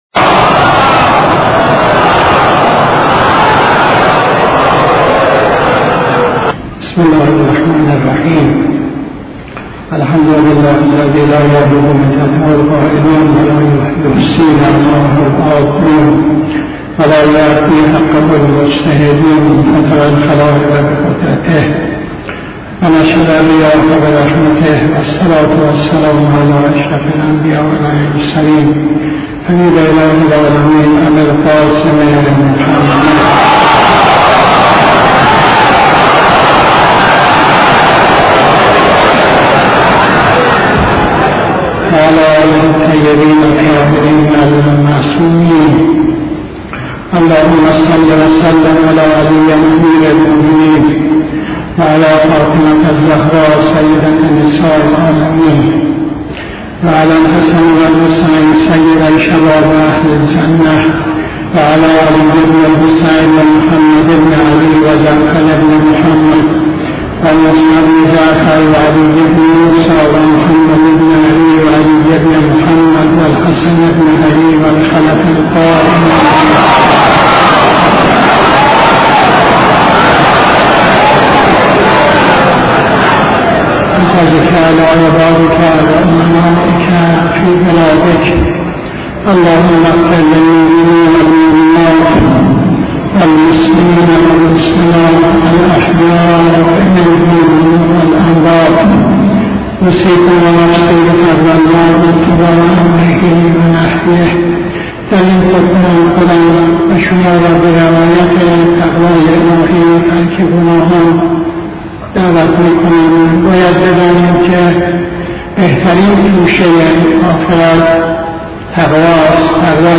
خطبه دوم نماز جمعه 30-11-83